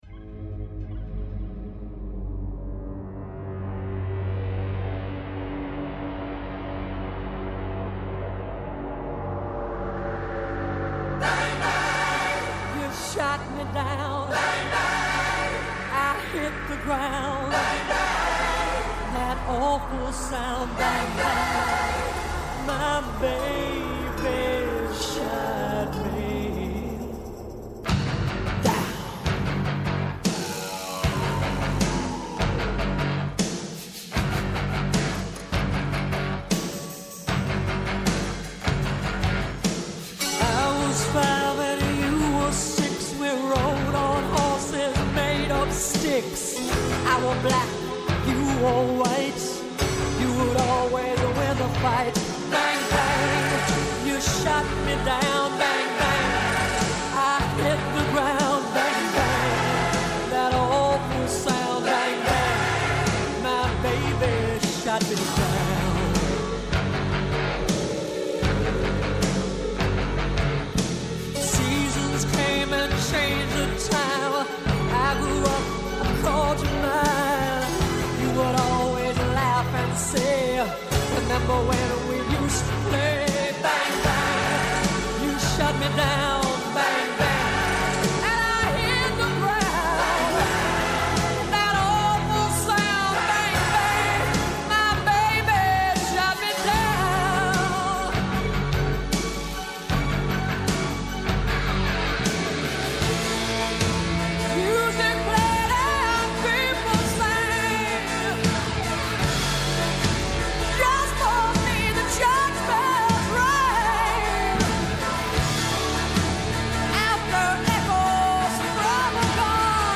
Από Δευτέρα έως Πέμπτη 11 με 12 το μεσημέρι στο Πρώτο Πρόγραμμα. ΠΡΩΤΟ ΠΡΟΓΡΑΜΜΑ Ναι μεν, Αλλα…